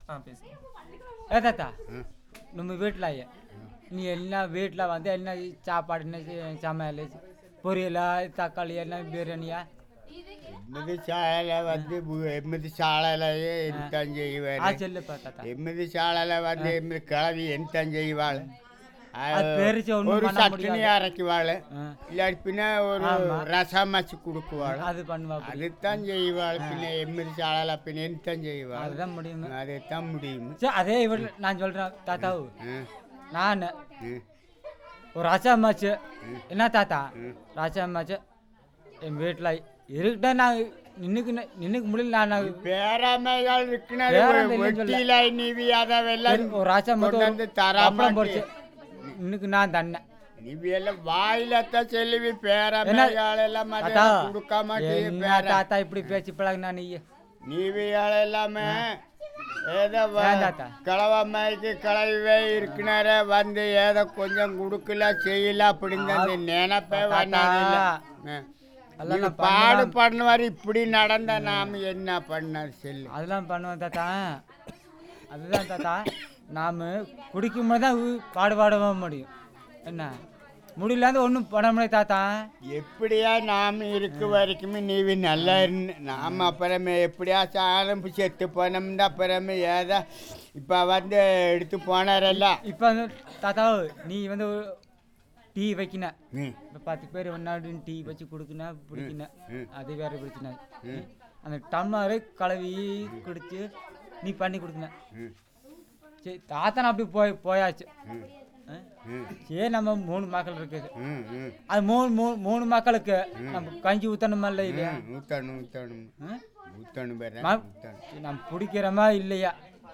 Conversation about food, employment
It is a staged conversation for the purpose of recording. The informants talk about how none of the previous documentors have brought any good to the community.